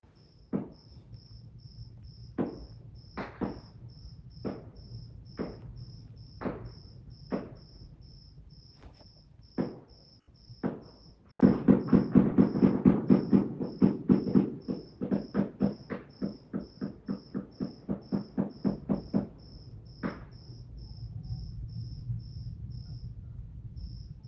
En redes sociales circulan audios de fragmentos del presunto tiroteo escenificado en la localidad de Colola por civiles armados.